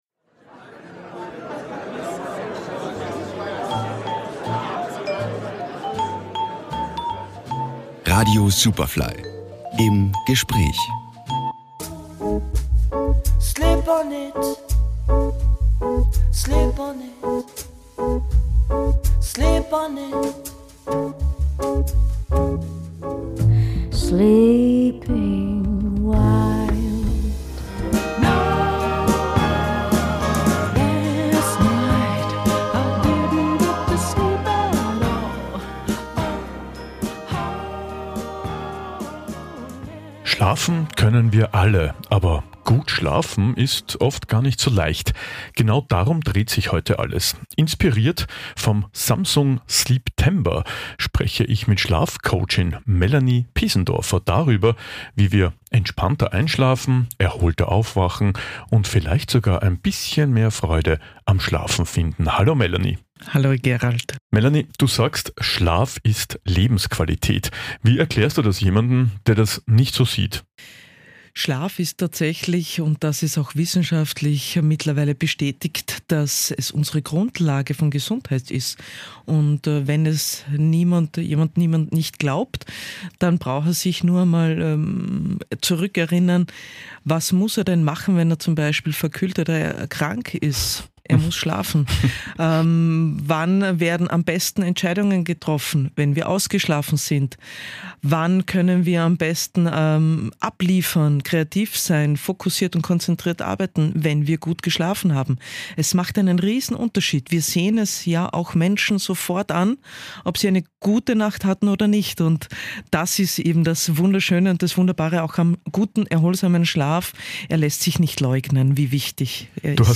Superfly Interviews